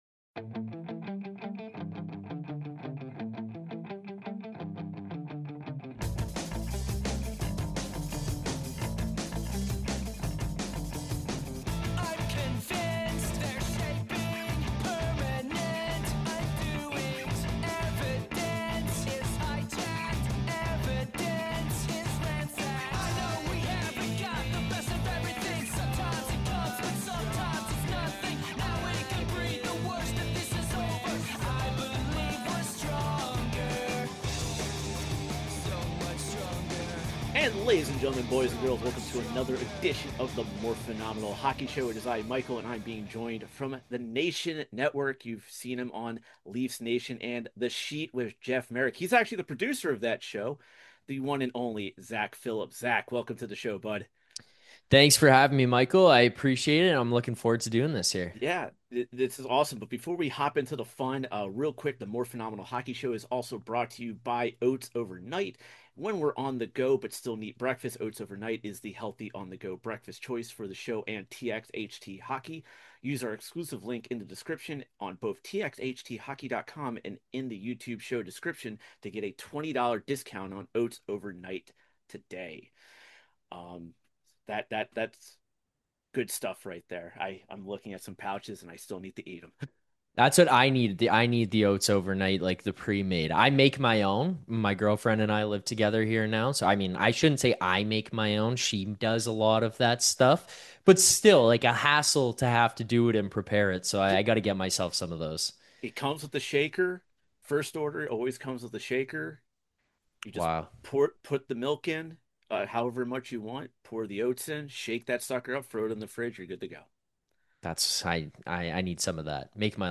Ladies and Gentlemen welcome to The Morphinominal Hockey Show for some fun hockey conversations with actors from the Power Rangers and TMNT franchises